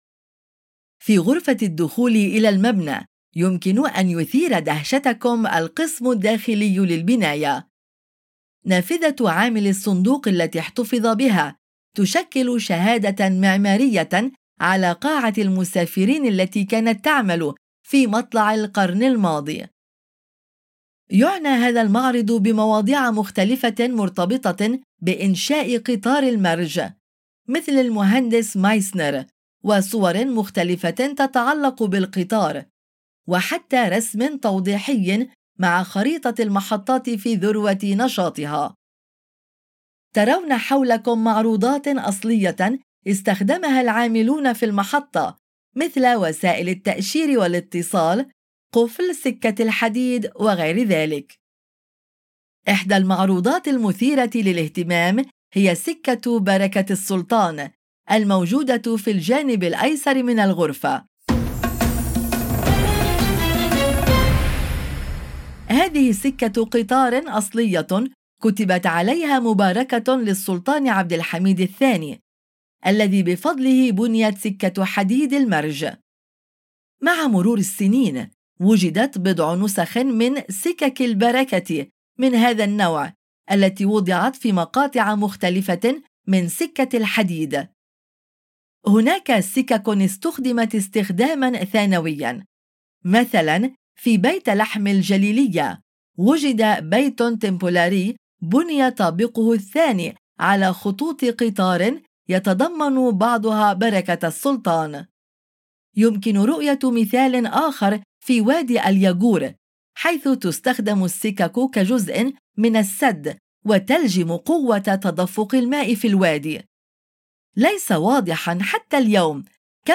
الصوت: يمكن إدخال بعض أصوات الهتاف الاصطناعي التي تظهر في القرص من المرشد إلى قصر توب كابي في إسطنبول